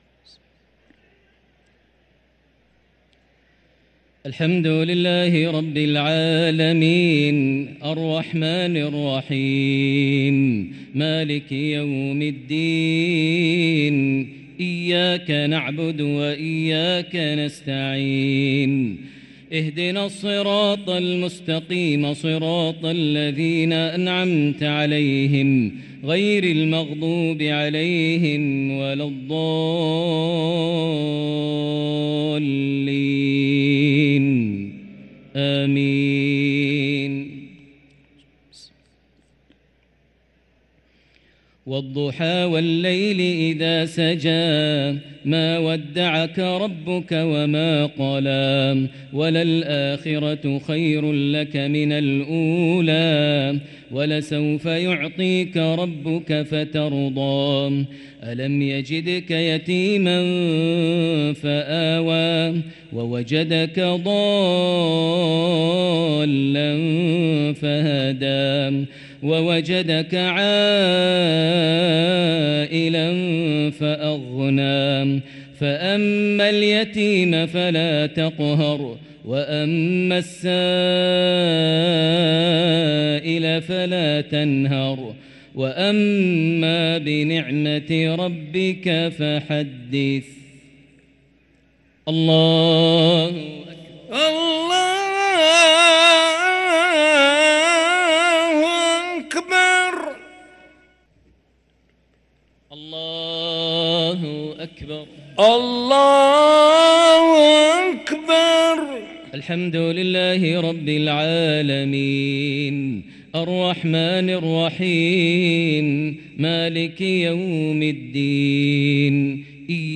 تلاوة لسورتي الضحى و العصر | عشاء الخميس 8-9-1444هـ > 1444 هـ > الفروض - تلاوات ماهر المعيقلي